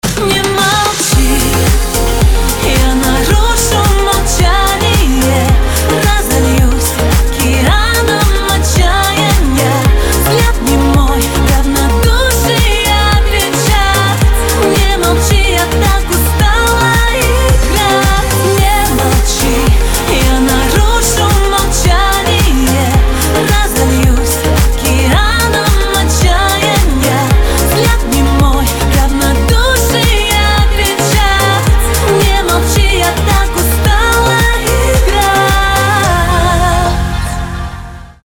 • Качество: 320, Stereo
поп
грустные